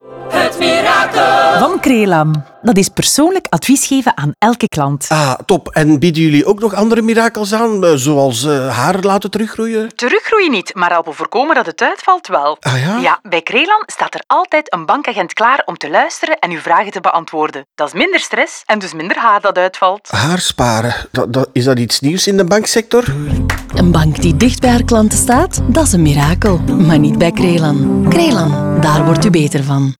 Na de week van de lancering volgen twee klassiekere spots van 30 seconden.
Crelan-Mirakel-Radio-Haren.wav